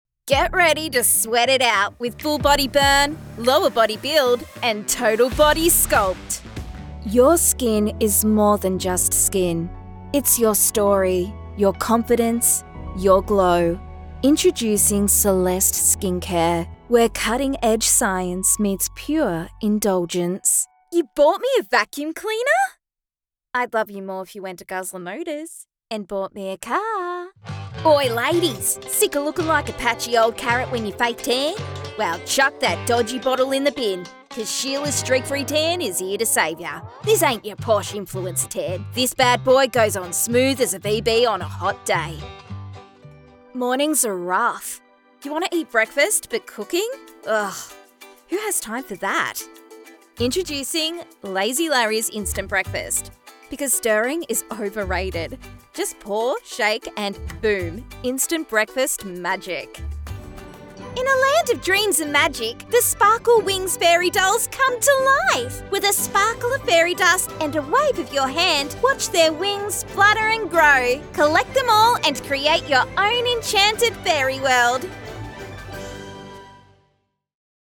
Female
Warm , versatile , dynamic and engaging . Large vocal range.
Radio Commercials
Variety Of Commercial Reads